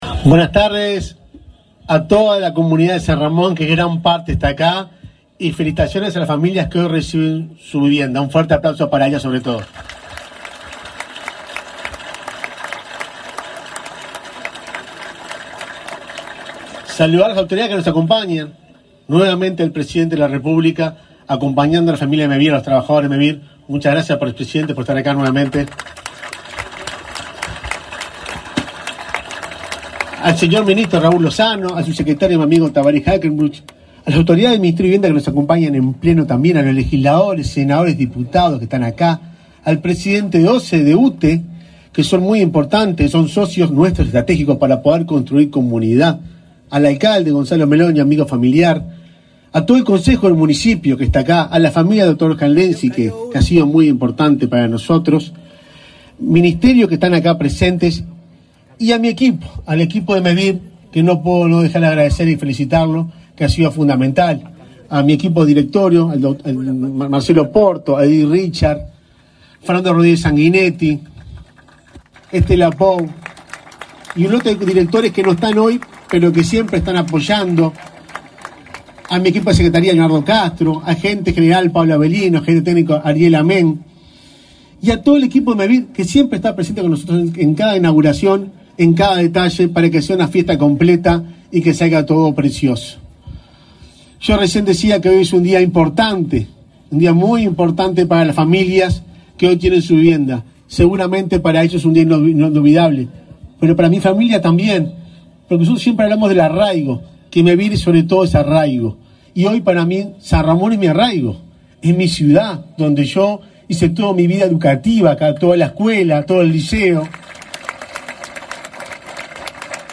Acto de inauguración del plan de viviendas de Mevir en San Ramón, Canelones
Acto de inauguración del plan de viviendas de Mevir en San Ramón, Canelones 18/10/2024 Compartir Facebook X Copiar enlace WhatsApp LinkedIn Mevir inauguró, este 18 de octubre, el plan de viviendas de Mevir “Dr. Oscar Lenzi”, en la localidad de San Ramón, en Canelones, con la presencia del presidente de la República, Luis Lacalle Pou. En el evento, disertaron el presidente de MEVIR, Juan Pablo Delgado, y el ministro de Vivienda y Ordenamiento Territorial, Raúl Lozano Bonet.